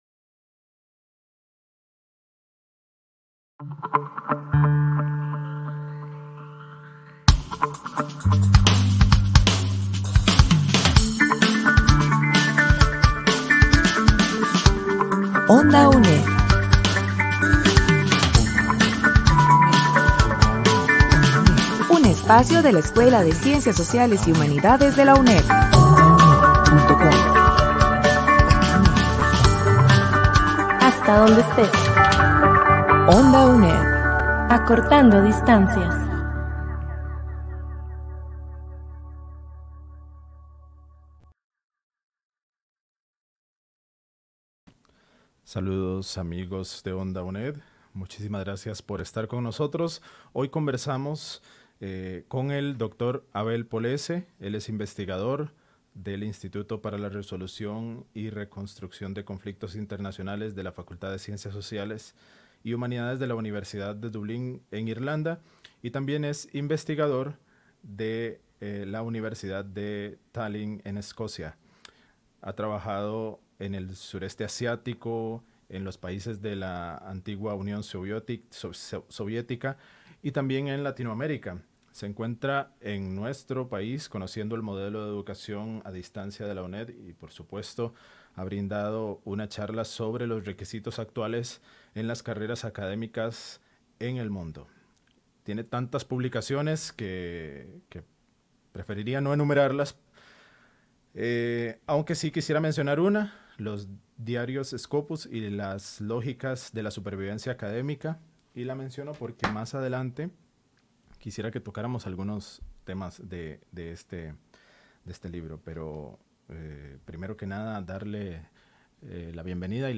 Programas de RADIO RADIO La Internacionalizacion, la investigacion y los retos de la UNED en estas areas Su navegador no soporta la reproducci�n de audio HTML5.